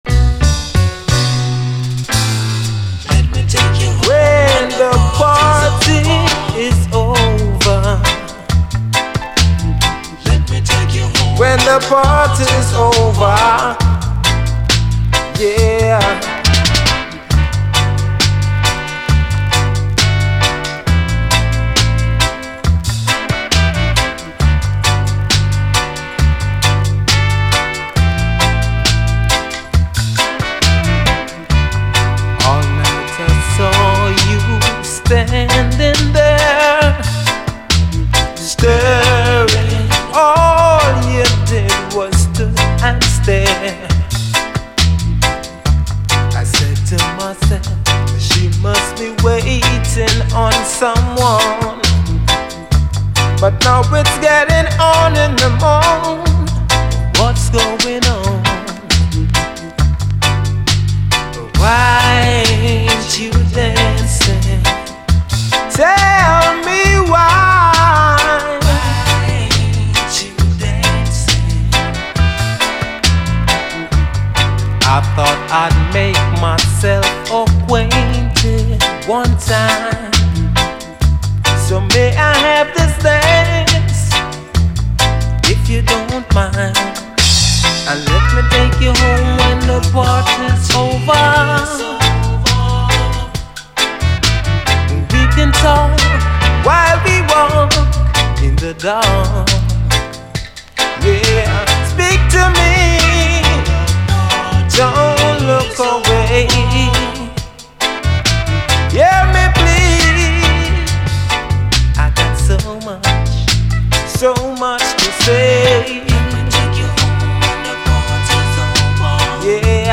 REGGAE
UKラヴァーズ10インチ